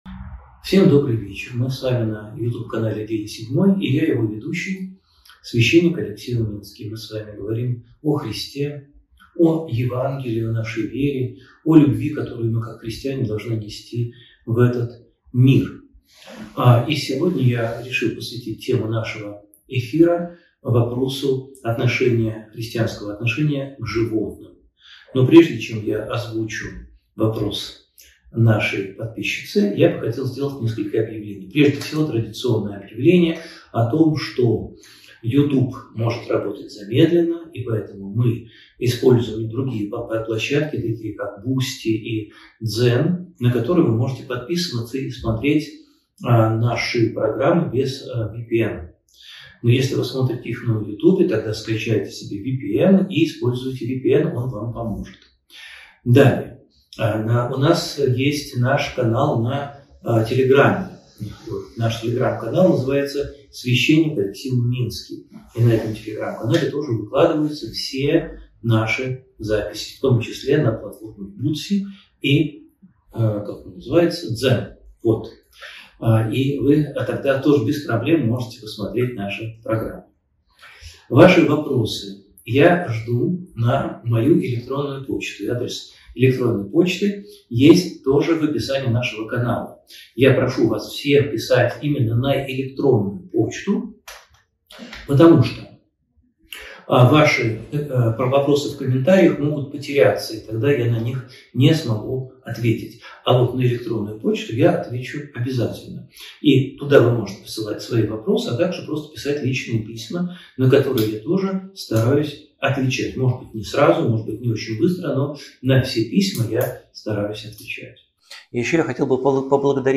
Эфир ведёт Алексей Уминский
«День седьмой» с Алексеем Уминским: Беседа о животных